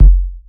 SouthSide Kick Edited (13).wav